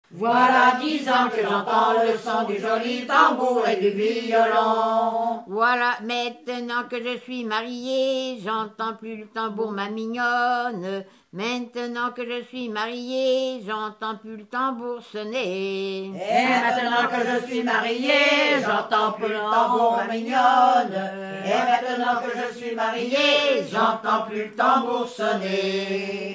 Fonction d'après l'analyste gestuel : à marcher
Genre énumérative
Pièce musicale inédite